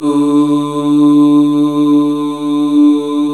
Index of /90_sSampleCDs/Club-50 - Foundations Roland/VOX_xMaleOoz&Ahz/VOX_xMale Ooz 1S